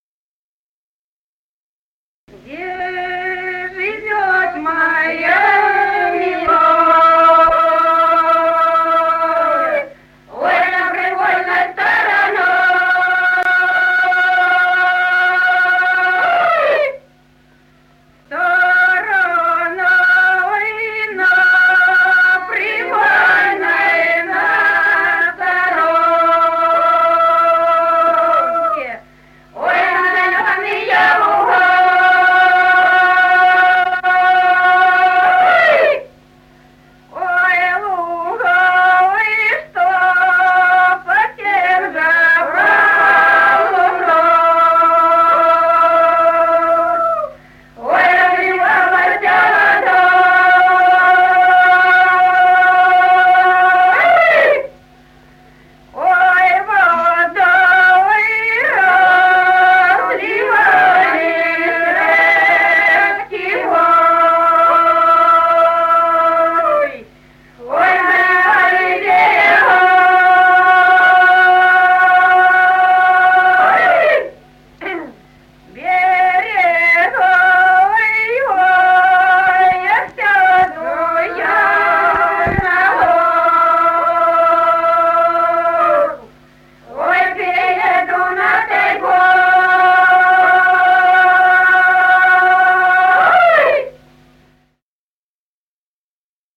Народные песни Стародубского района «Где живёт моя милая», весняная девичья.
с. Курковичи.